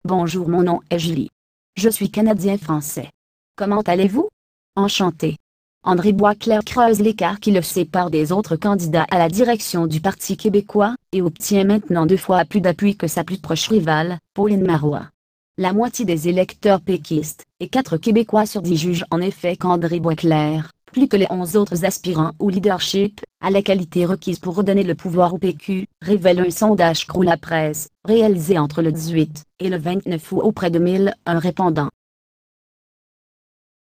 Texte de d�monstration lu par Julie (Nuance RealSpeak; distribu� sur le site de Nextup Technology; femme; fran�ais canadien)